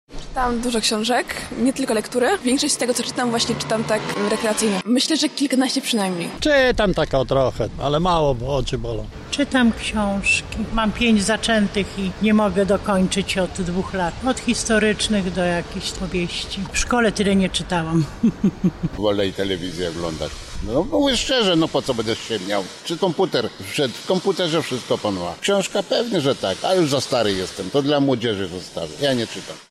Nasi reporterzy zapytali mieszkańców miasta, czy lubią czytać, a jeżeli tak – to jakie pozycje wybierają.